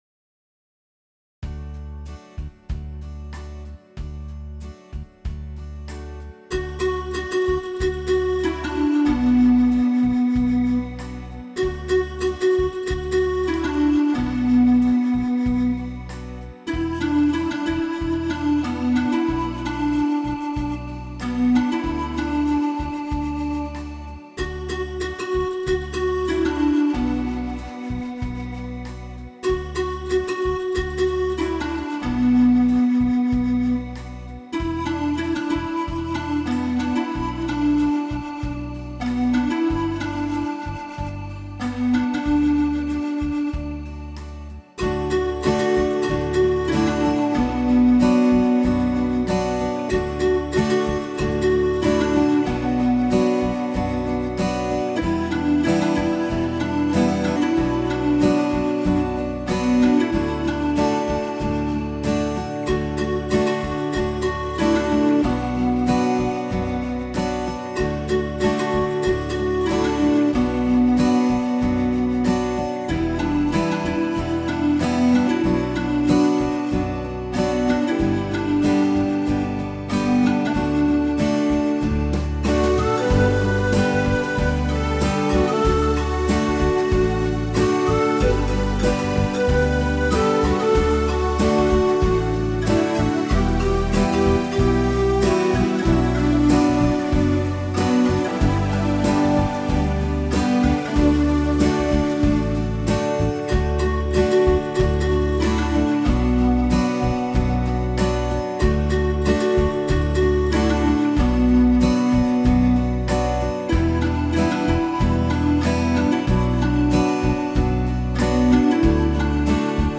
Style EasyBallad    Pad Steel8BeatSstrum1
Voices   PanPipes + 80sHarpvox
PanPipes + BrightPopPad